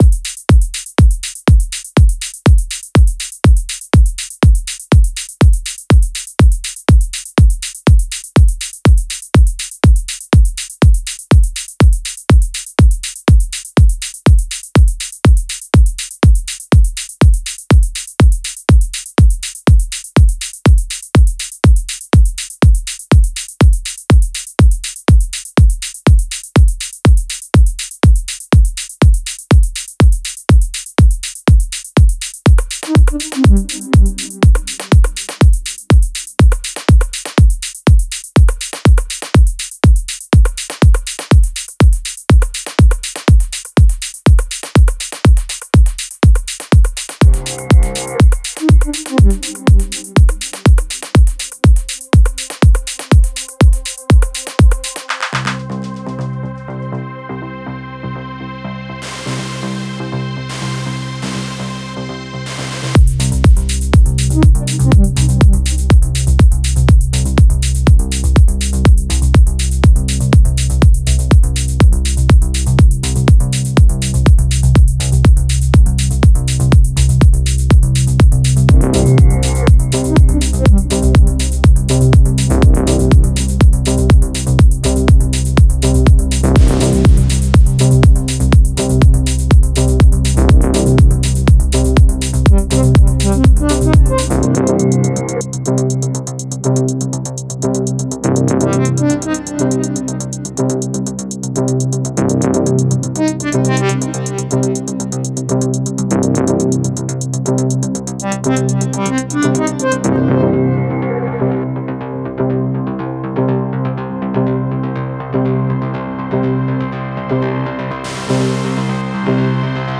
Стиль: Progressive House / Melodic Techno